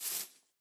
step / grass2